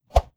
Close Combat Swing Sound 78.wav